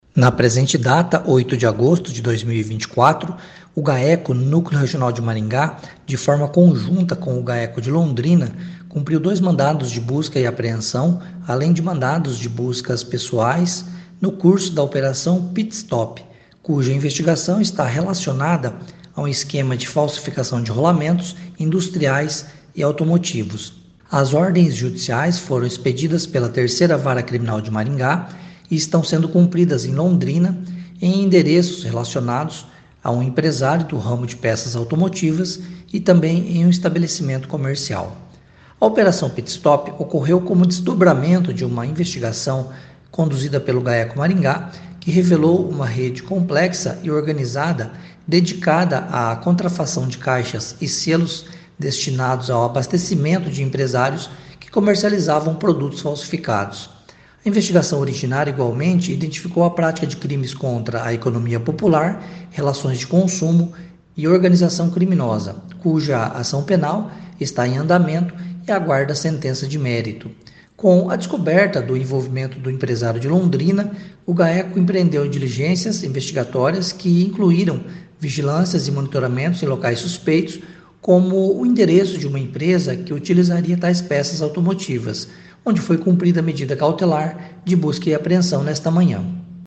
Ouça o que diz o promotor de Justiça Marcelo Alessandro Gobbato: